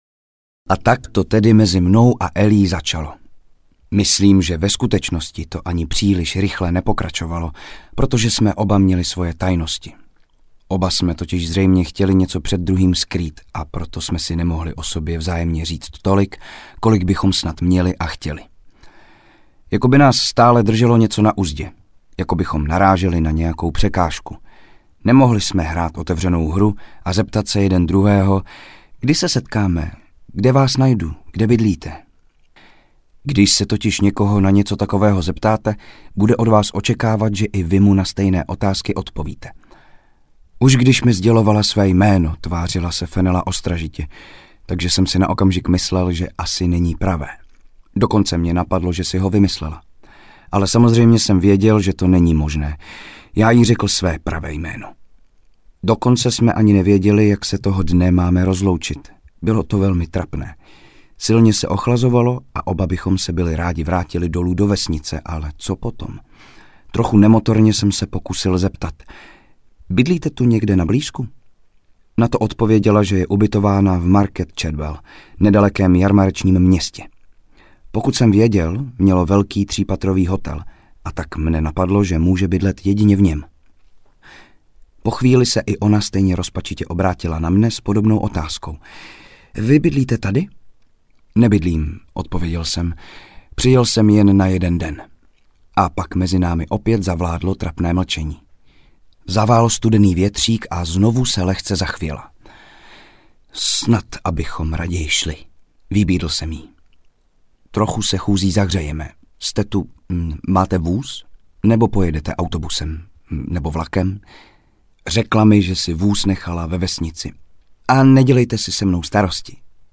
Nekonečná noc audiokniha
Ukázka z knihy